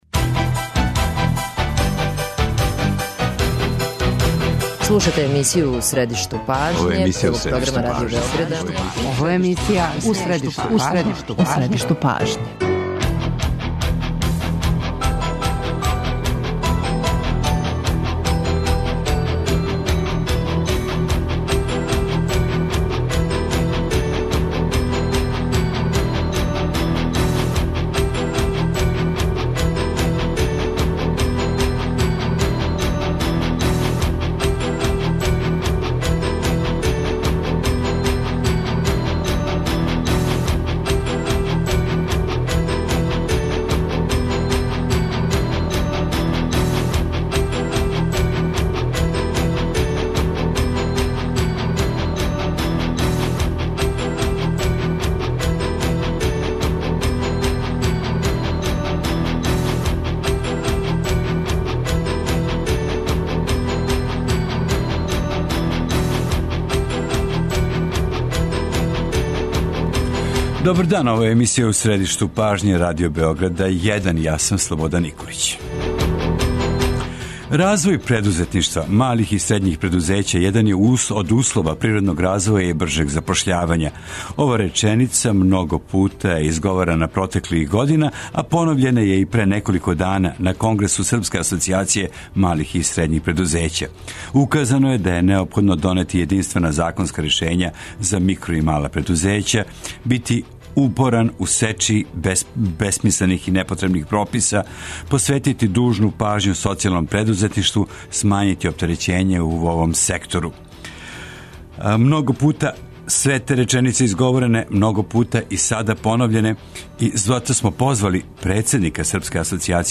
Гости емисије У средишту пажње су предузетнници из разних крајева Србије